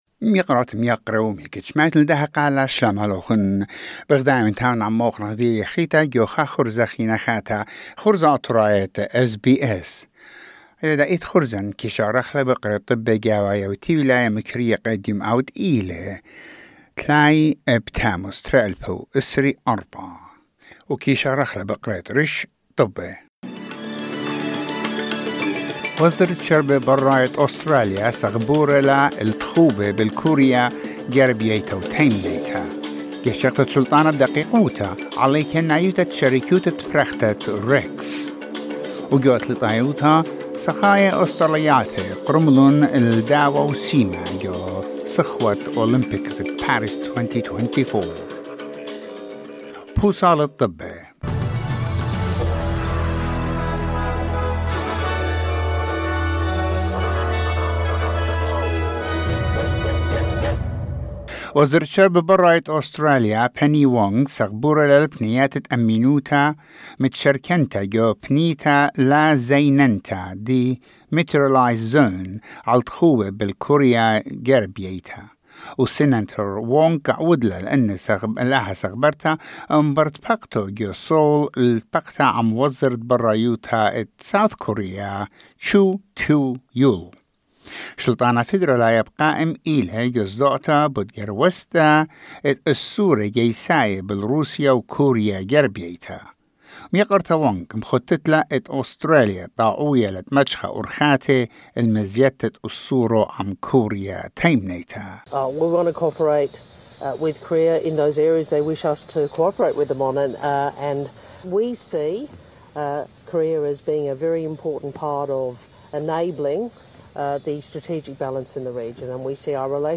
Assyrian news bulletin: 30 July 2024